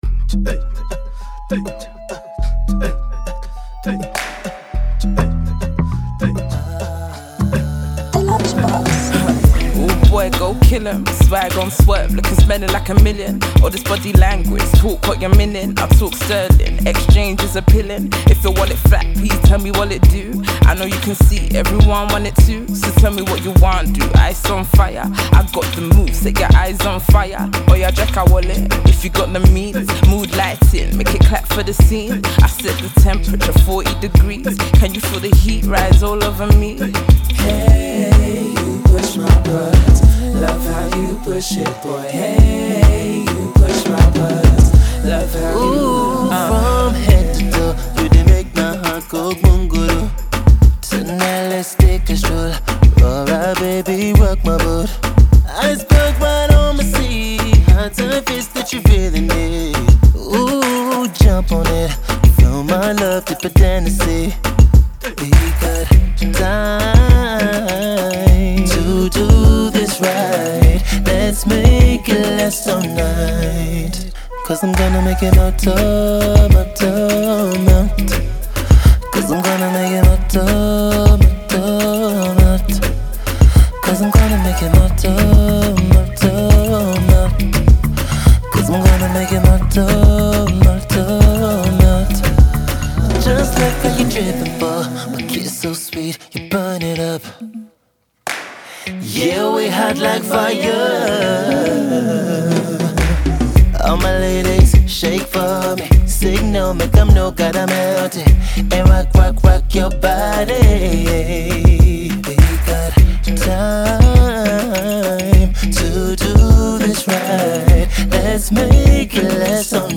British Nigerian singer
With a  fusion of African and Reggae influences
delivering rap verses